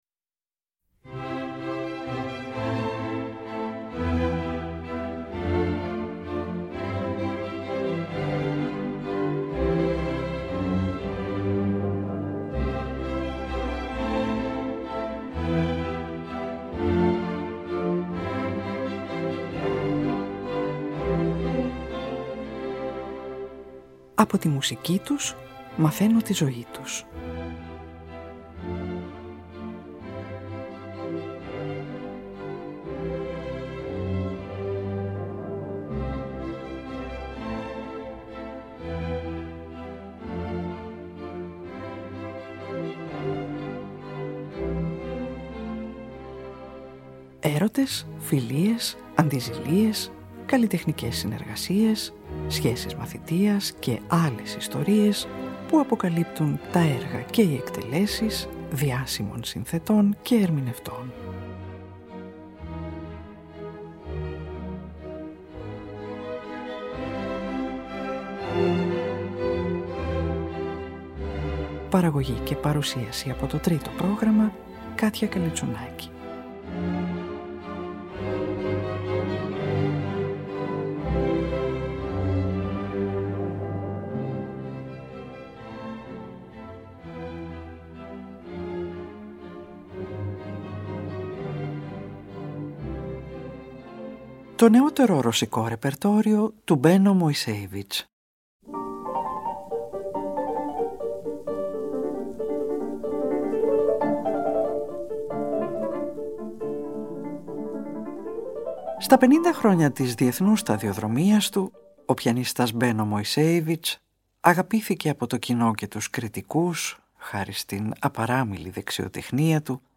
Ο Ρωσοβρετανός πιανίστας
Από ηχογραφήσεις που έκανε στο Λονδίνο την περίοδο 1942-1955